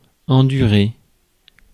Ääntäminen
US RP : IPA : /ˈsʌfə/ GenAm: IPA : /ˈsʌfɚ/